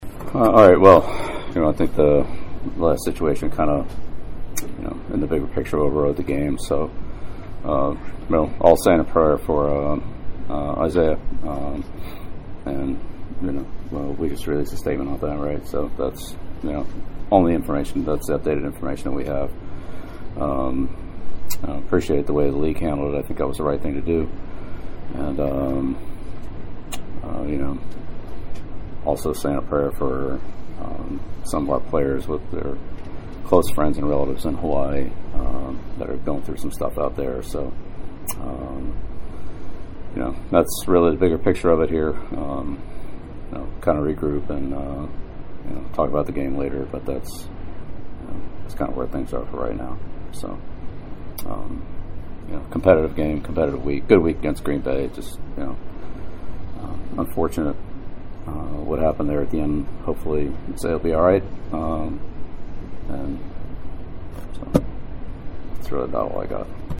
From the media room next to the New England locker room, Belichick did the same: